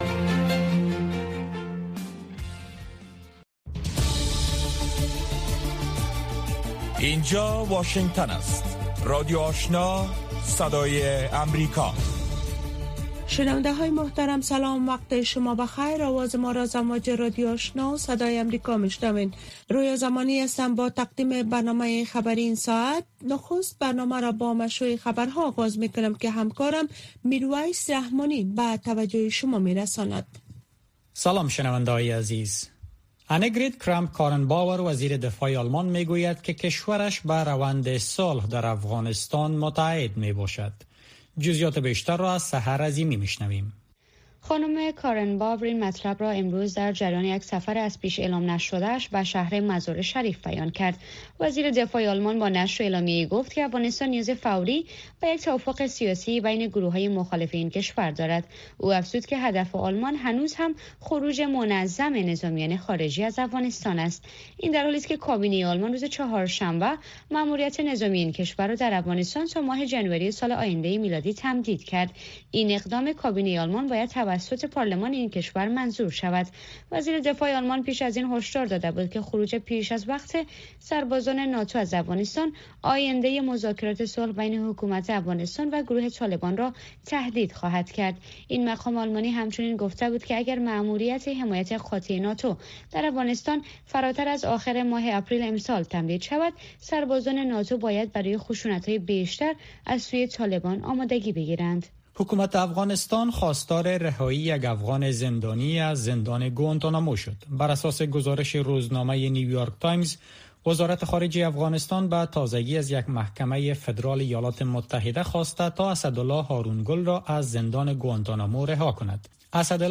در نخستین برنامه خبری شب خبرهای تازه و گزارش های دقیق از سرتاسر افغانستان، منطقه و جهان فقط در سی دقیقه.